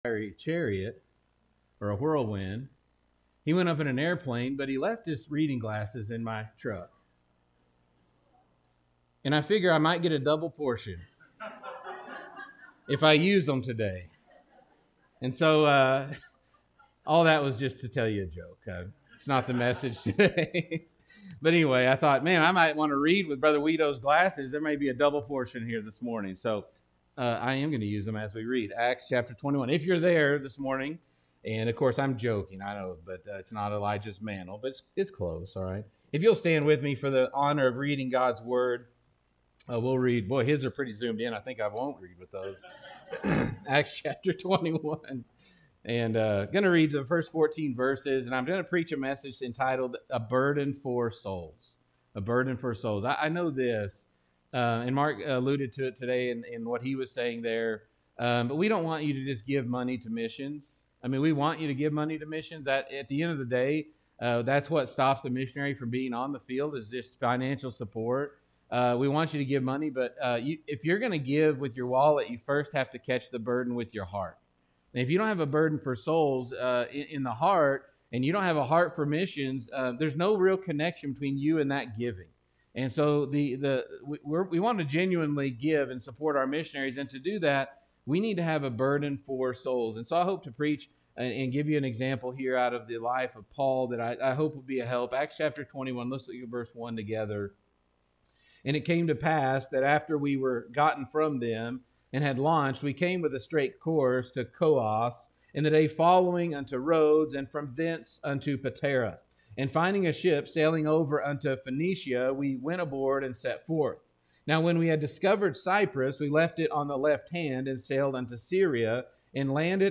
Missions Conference Final Service